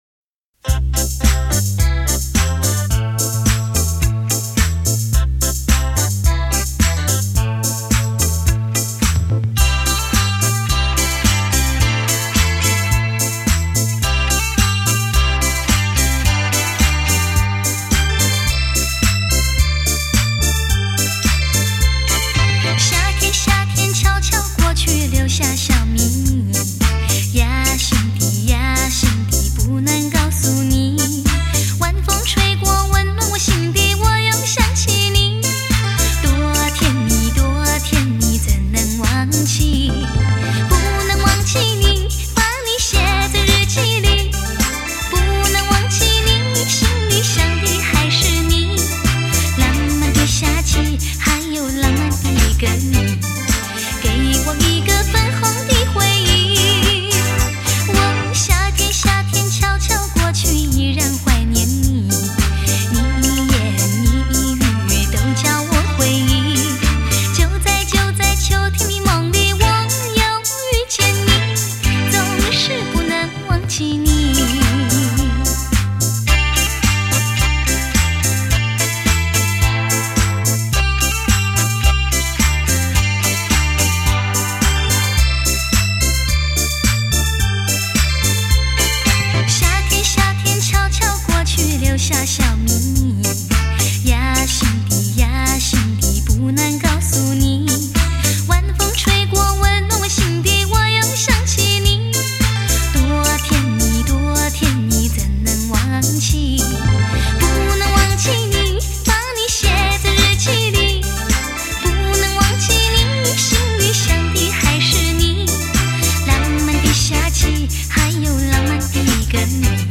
心醉神迷的甜美之音